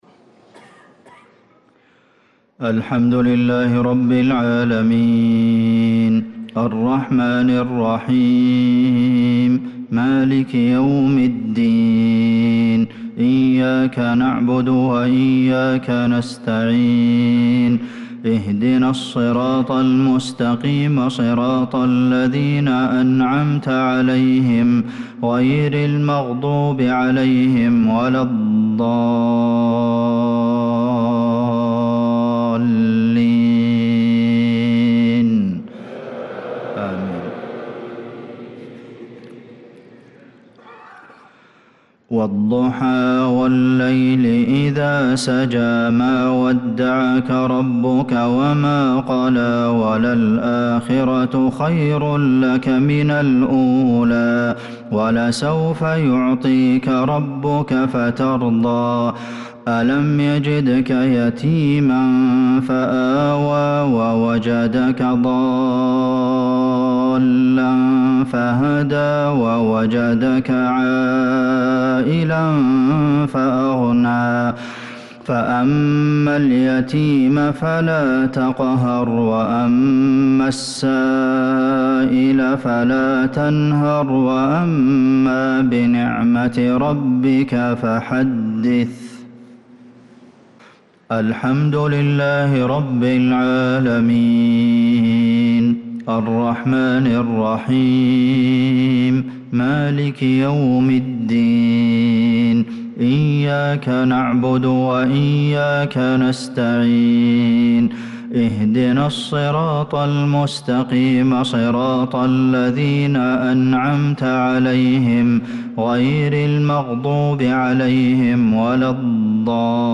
صلاة المغرب للقارئ عبدالمحسن القاسم 18 ربيع الآخر 1446 هـ